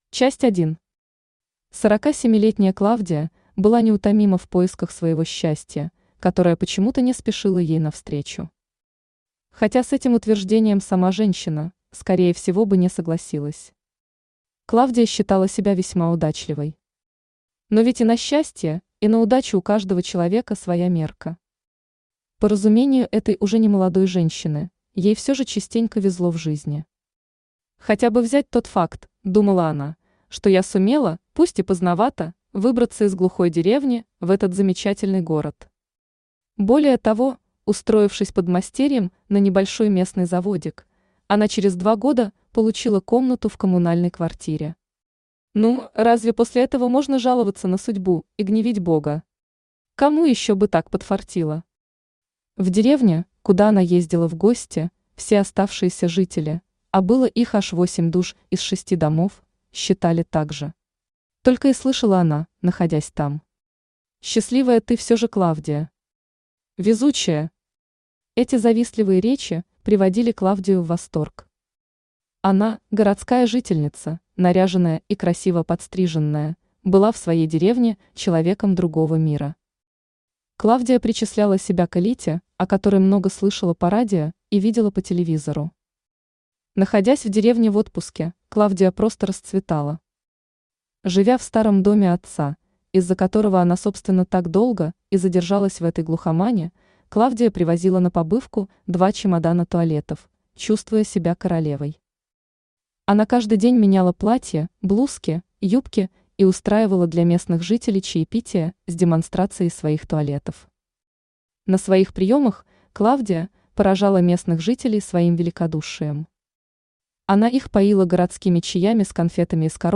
Аудиокнига Зашитые бриллианты | Библиотека аудиокниг
Aудиокнига Зашитые бриллианты Автор Жанна Светлова Читает аудиокнигу Авточтец ЛитРес.